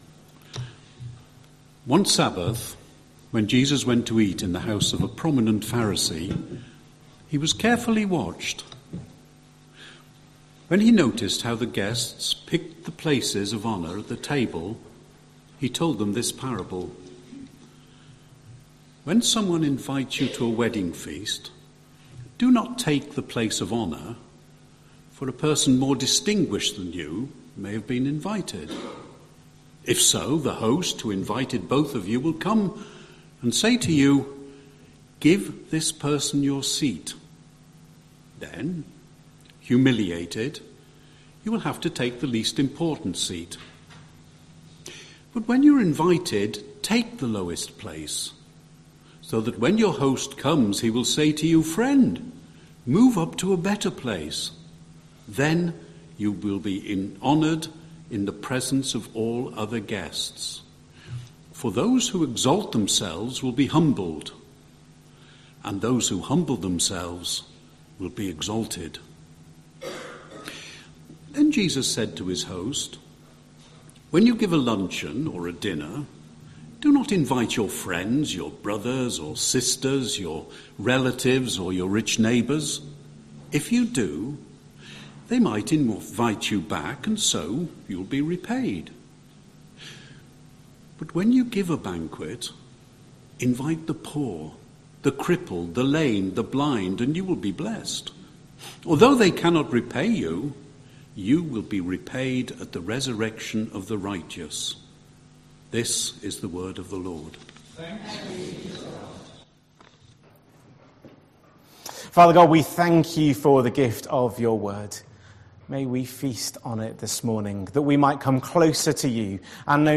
31st August 2025 Sunday Reading and Talk - St Luke's